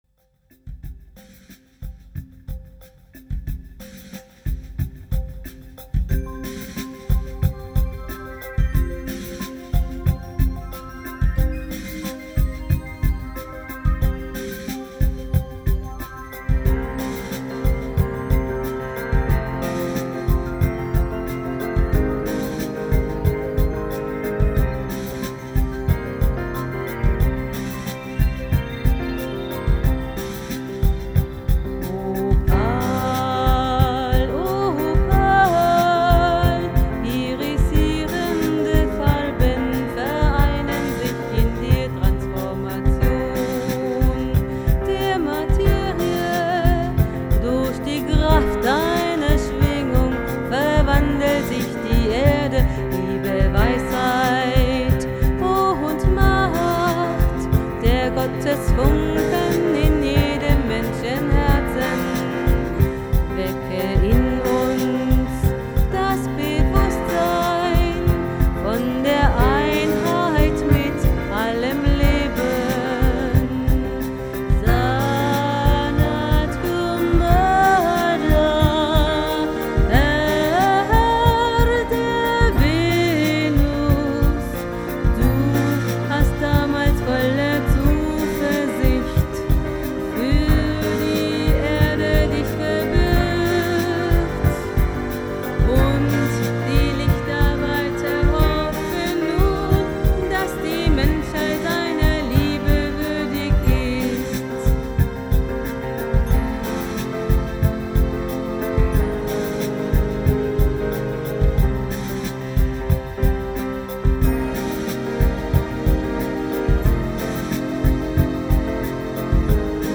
Themenwelt Kunst / Musik / Theater Musik Pop / Rock